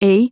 OCEFIAudio_ru_LetterA.wav